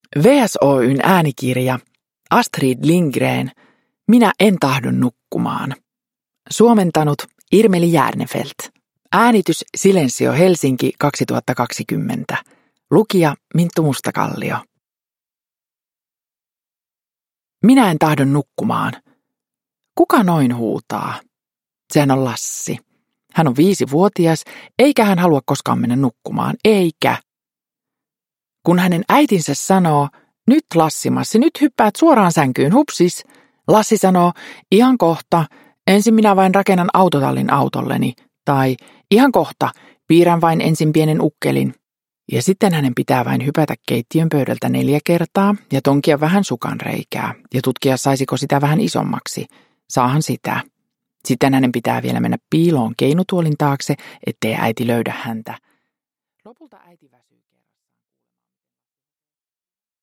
Minä en tahdo nukkumaan! – Ljudbok – Laddas ner
Uppläsare: Minttu Mustakallio